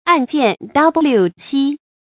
案剑瞋目 àn jiàn chēn mù
案剑瞋目发音